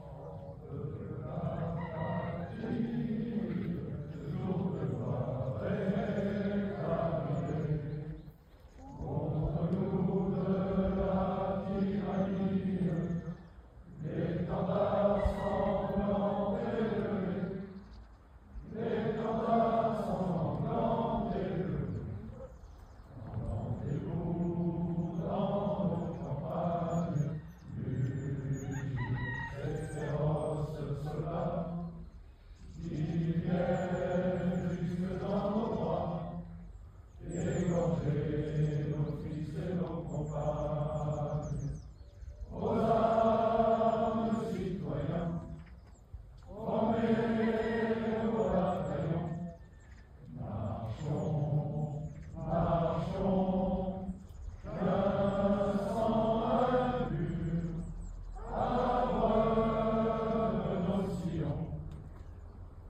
Tous les ans, une cérémonie a lieu lors de la Saint Michel (fête des parachutistes) en deux temps
Deuxième temps dans le cimetière de ROM, là où reposent ces soldats
Marseillaise chantée le 29/09/2024